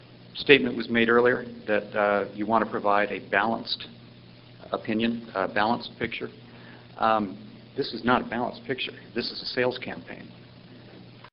The following audio excerpts from the public record are from an October 5, 2005 joint meeting of the council and planning board.
The turnout was so large that the meeting had to be held at North Hunterdon High School.
Ironically, another citizen objected to the compromised nature of the information presented to the public by the planning board and confronted the attempt to manipulate public opinion: